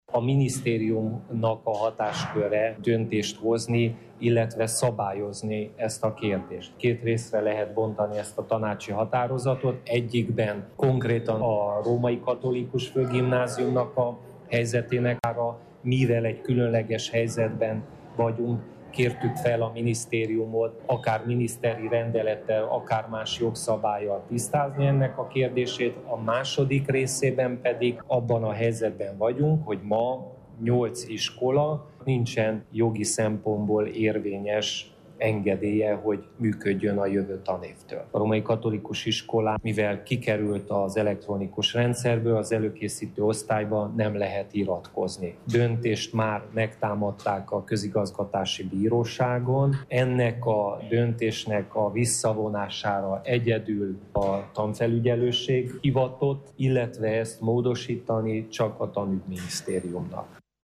Peti András helyi tanácsost hallják: